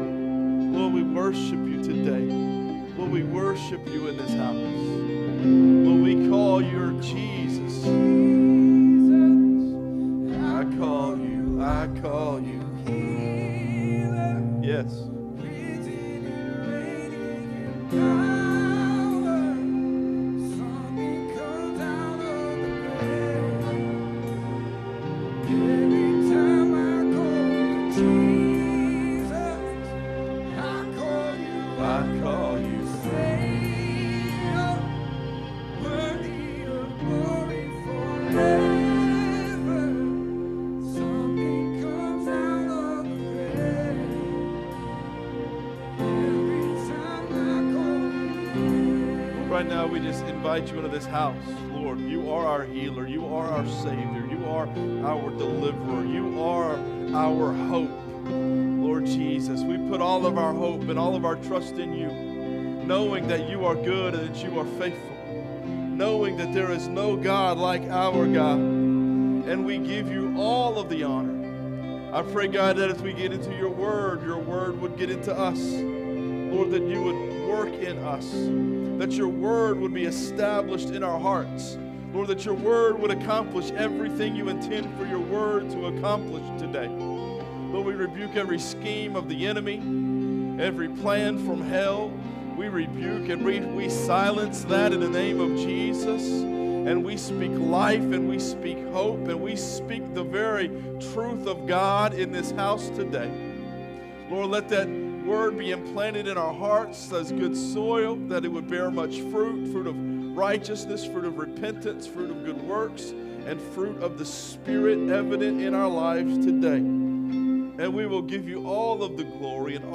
Sermon Notes
Because of the way the Spirit moved in our gathering, and prompted me to deliver them differently, both service time sermons are shared below.